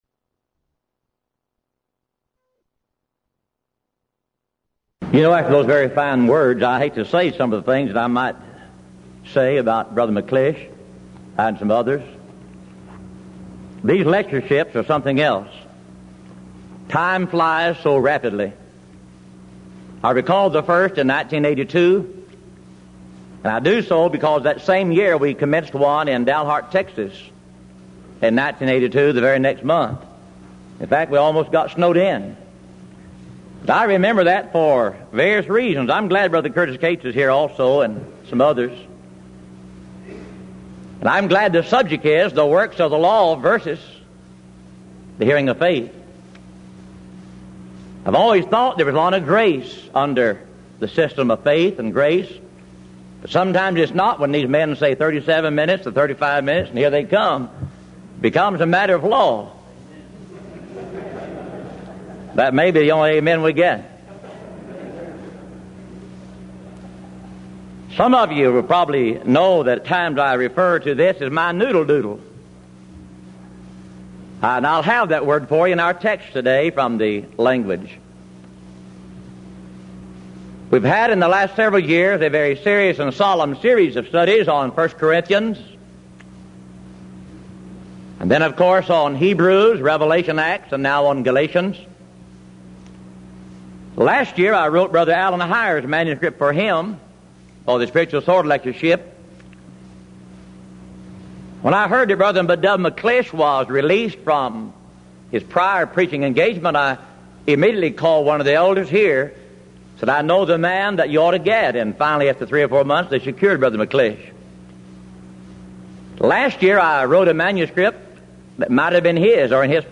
Event: 1986 Denton Lectures
lecture